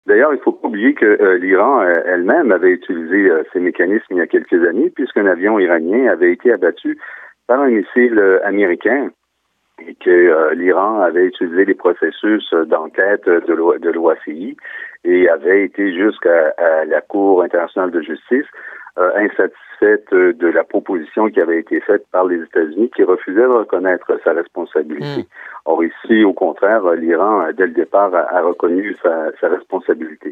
entrevistó al abogado